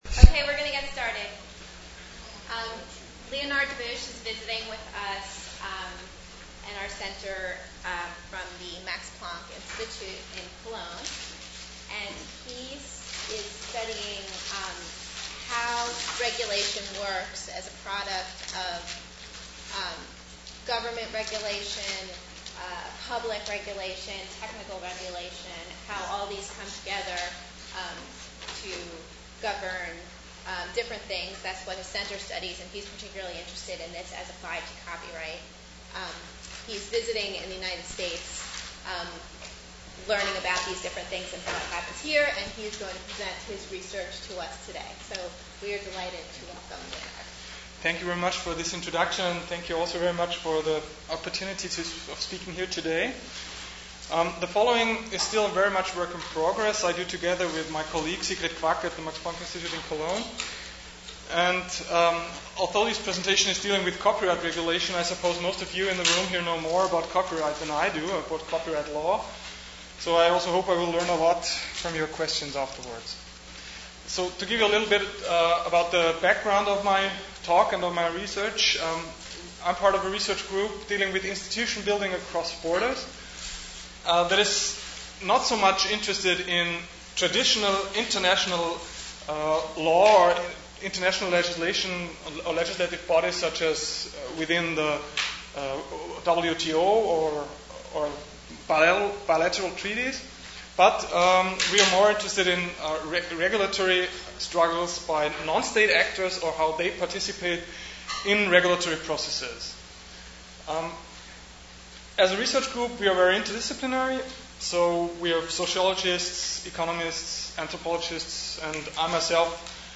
Talk "Copyright Dispute" (slides)